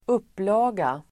Uttal: [²'up:la:ga]